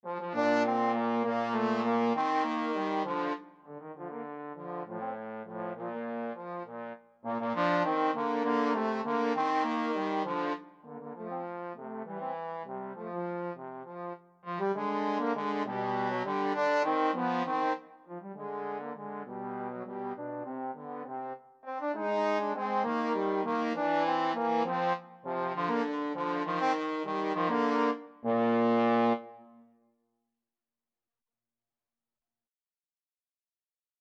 Trombone 1Trombone 2
3/8 (View more 3/8 Music)
Classical (View more Classical Trombone Duet Music)